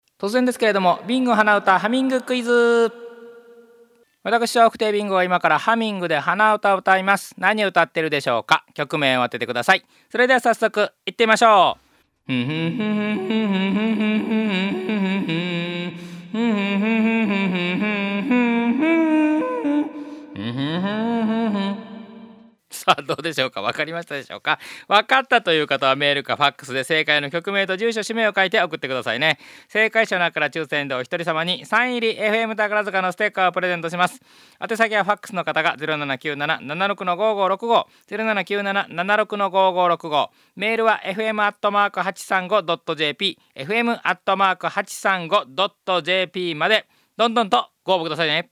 鼻唄クイズのヒントが聞けます。